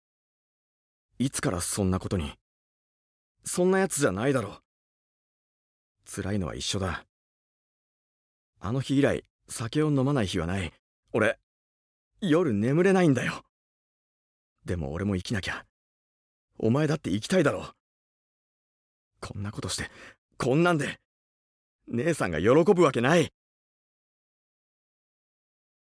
ボイスサンプル
台詞5